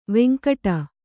♪ veŋkaṭa